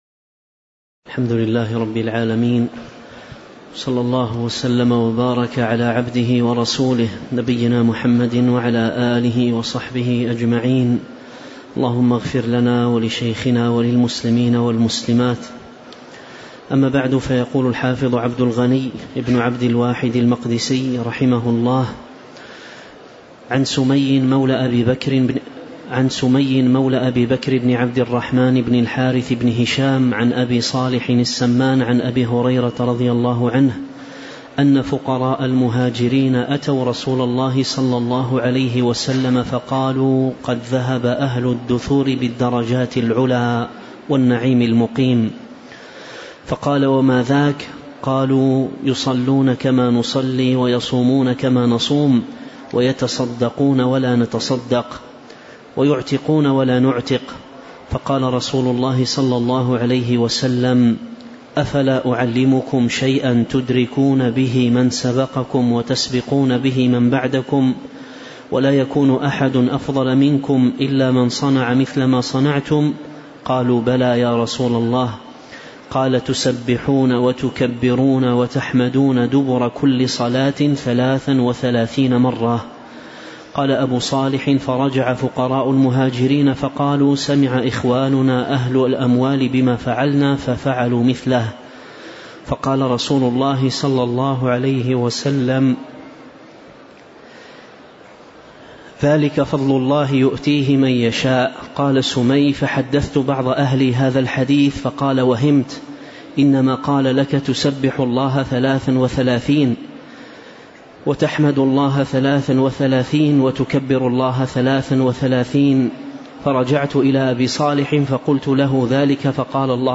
تاريخ النشر ١٢ جمادى الأولى ١٤٤٤ هـ المكان: المسجد النبوي الشيخ